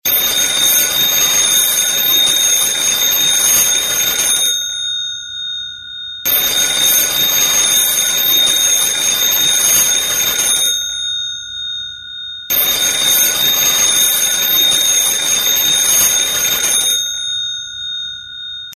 아련한 향수를 자극하는 ‘진짜’ 아날로그 전화 벨소리 모음입니다.
고전 벨소리 MP3 버전
Old-Phone-Long-Ring2.mp3